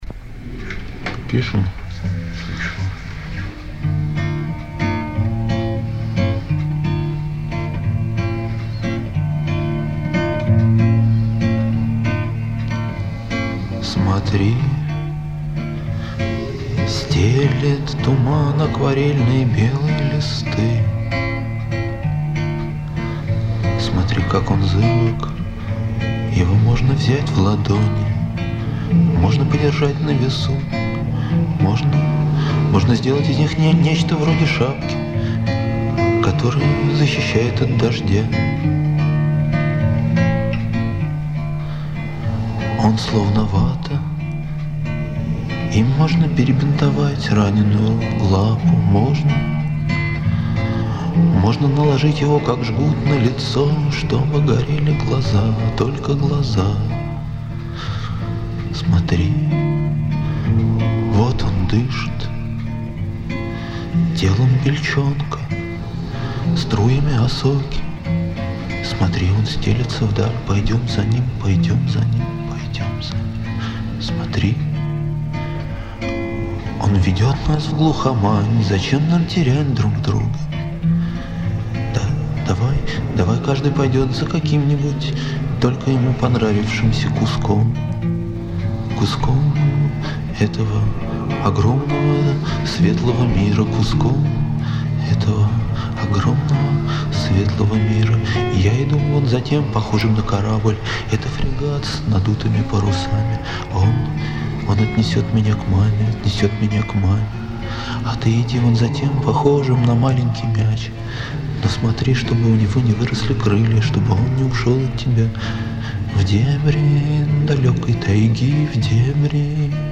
в жанре психоделической акустики
вокал
гитара     Обложка